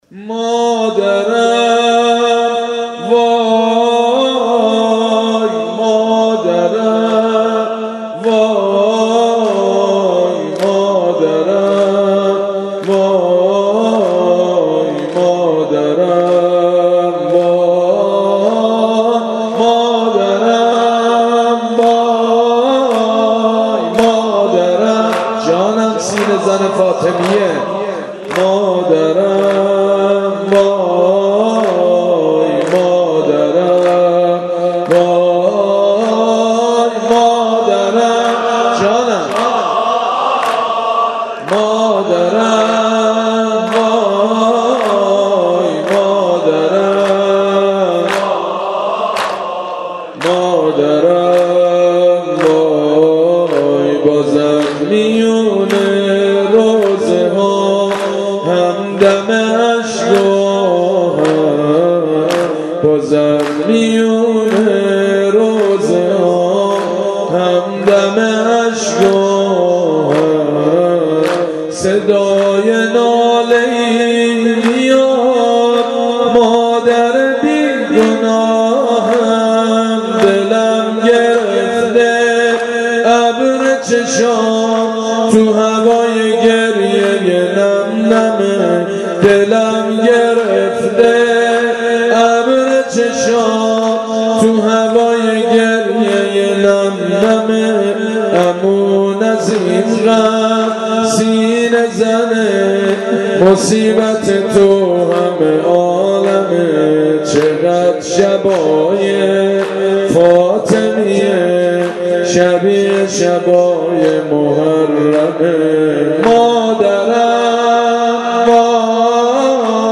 صوت مداحی سید مجید بنی فاطمه در حسینیه اوین
روضه سید مجید بنی فاطمه
سینه زنی